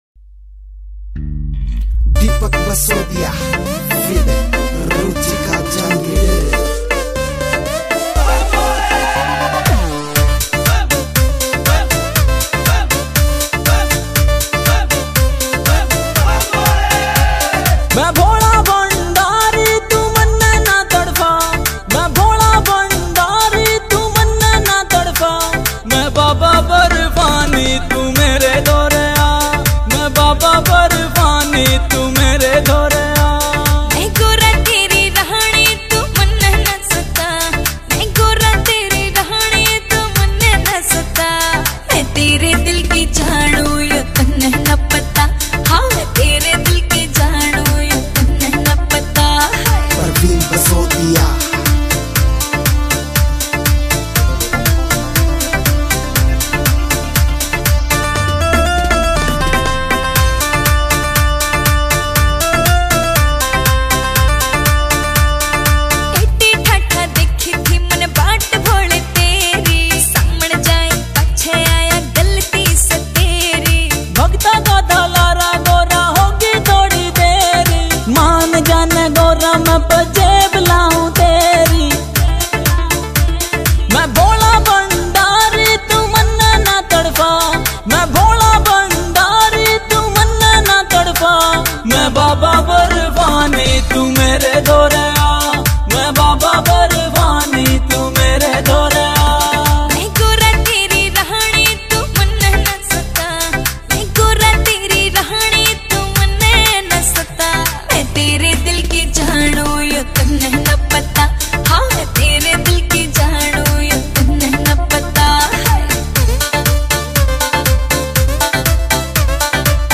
Bhakti Songs
» Haryanvi Songs